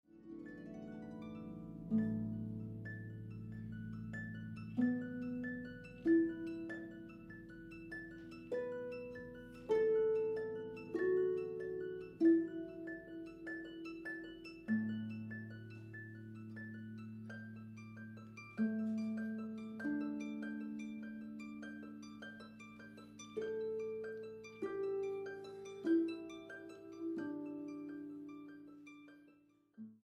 Dos arpas
arpista
Boonkker Audio Tacubaya, Ciudad de México.